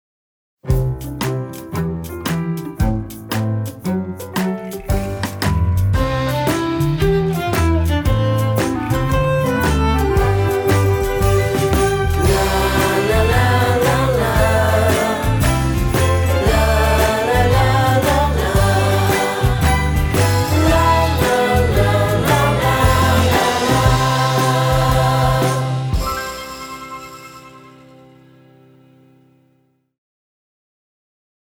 > Vocals
> Strings
> Drums
INSTRUMENTAL ACOUSTIC / CLASSIC